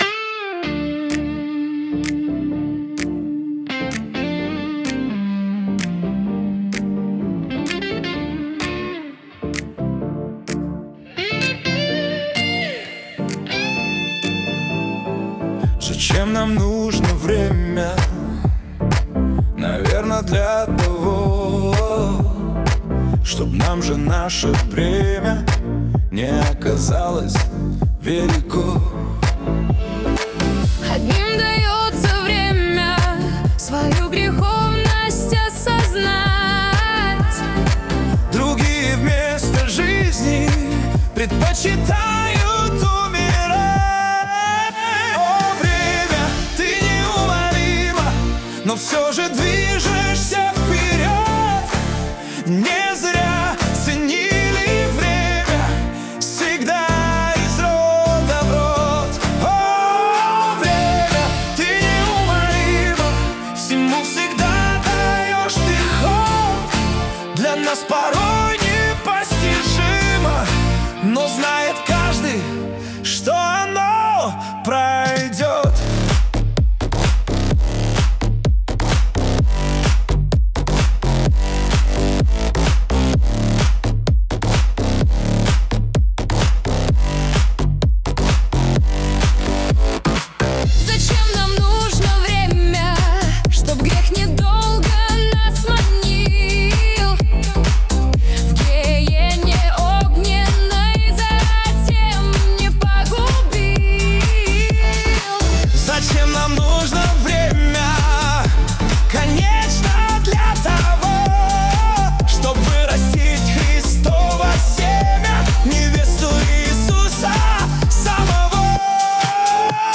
песня ai
152 просмотра 476 прослушиваний 39 скачиваний BPM: 128